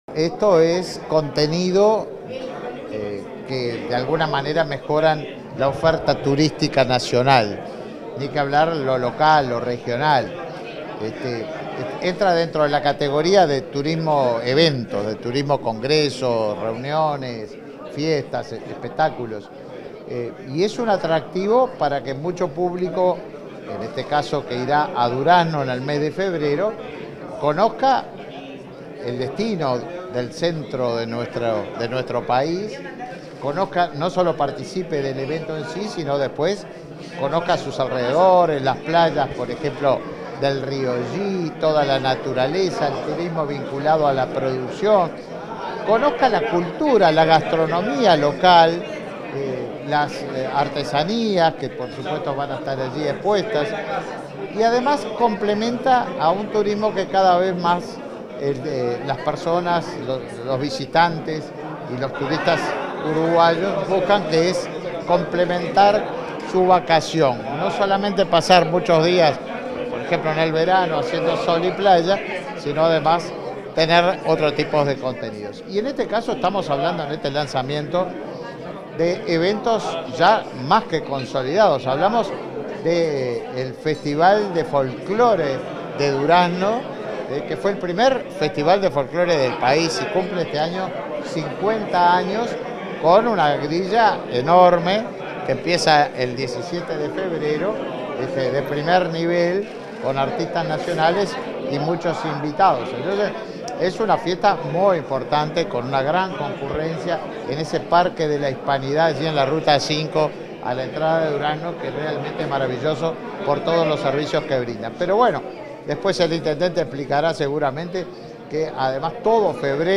Declaraciones del ministro de Turismo, Tabaré Viera
Declaraciones del ministro de Turismo, Tabaré Viera 16/01/2024 Compartir Facebook X Copiar enlace WhatsApp LinkedIn Tras el lanzamiento de la 50.ª edición del Festival Nacional e Internacional del Folclore y Encuentro Gaucho, el ministro de Turismo, Tabaré Viera, realizó declaraciones a la prensa.
Viera prensa.mp3